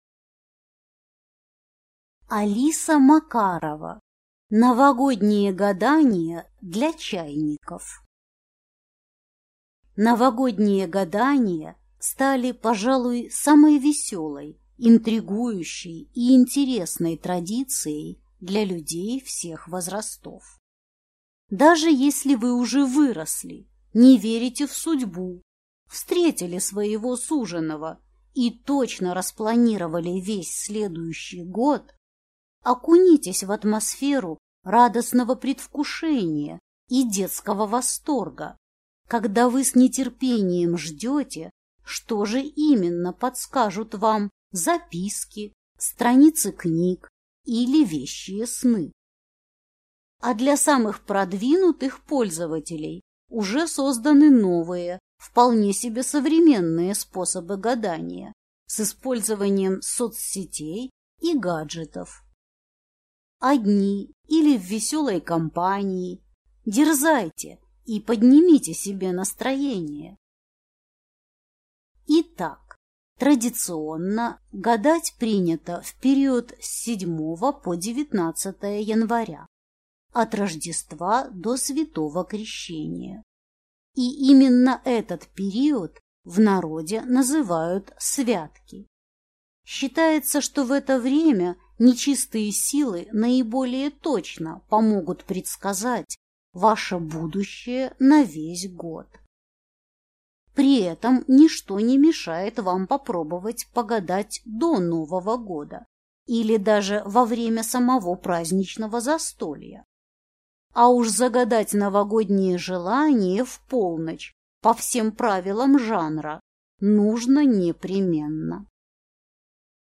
Аудиокнига Новогодние гадания для чайников | Библиотека аудиокниг